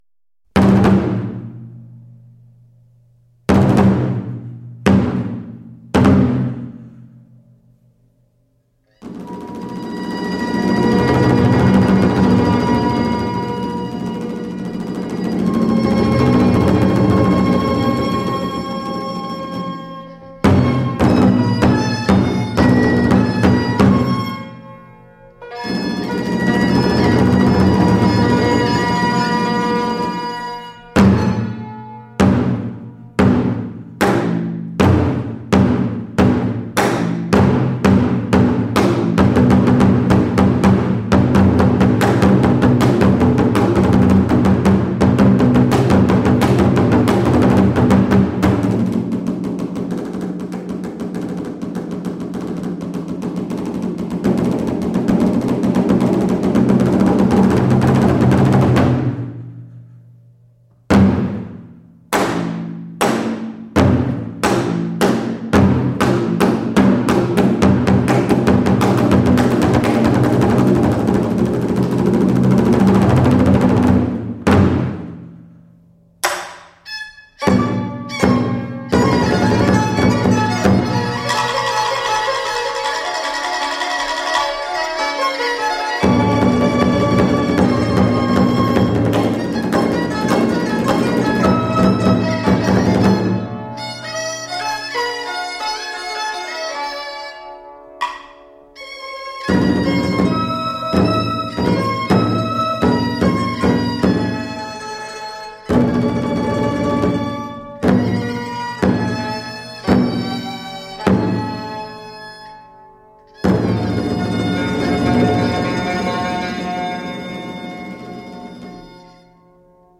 音响迷梦寐以求的超级精选音乐，无国界最具代表发烧名盘